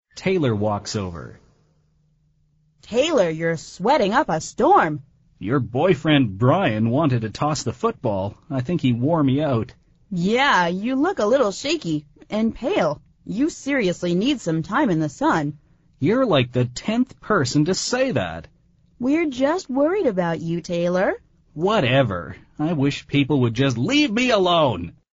美语会话实录第203期(MP3+文本):Sweat up a storm!